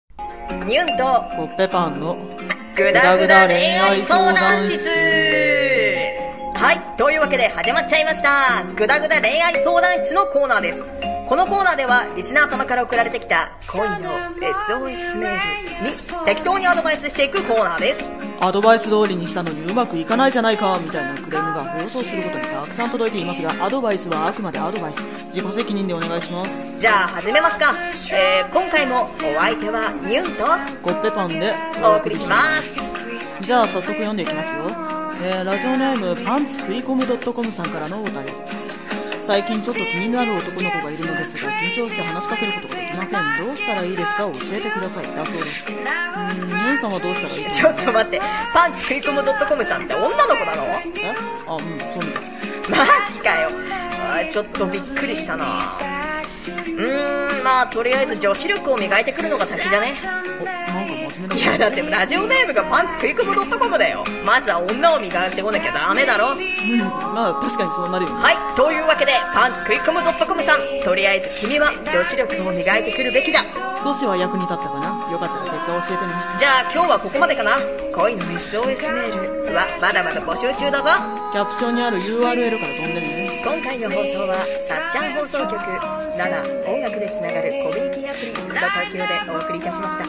【ラジオ風声劇】ぐだぐだ恋愛相談室